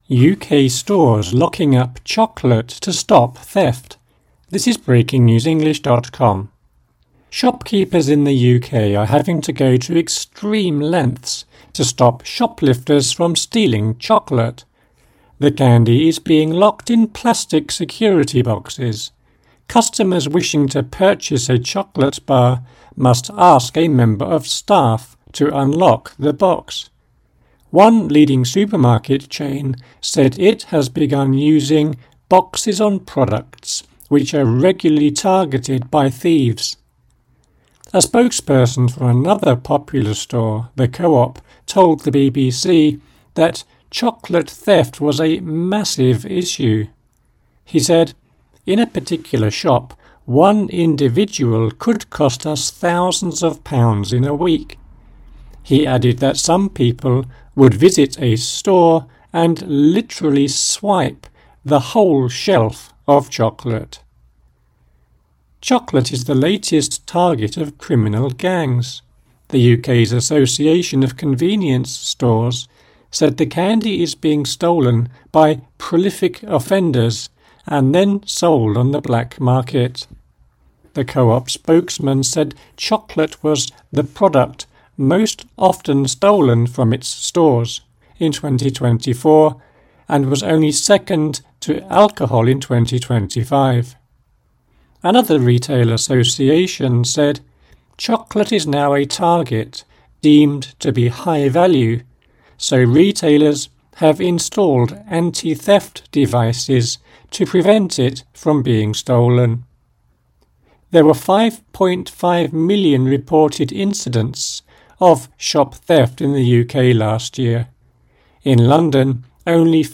AUDIO (Slow)